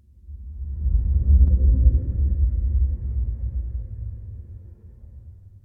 Ambient3.ogg